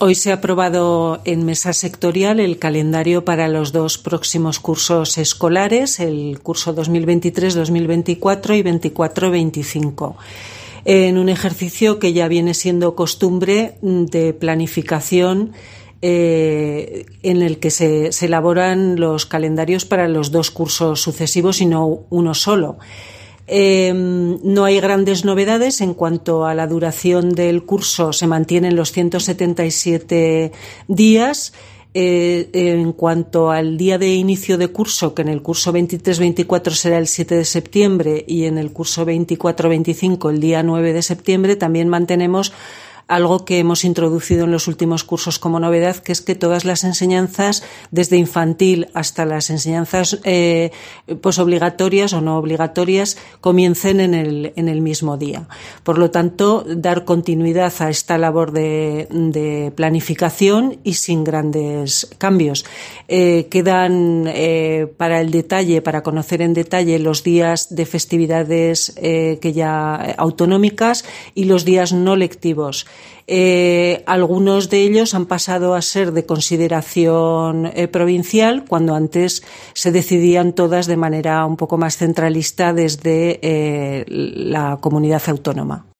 Olga Alastruey, directora general de Personal, explica los detalles del calendario escolar.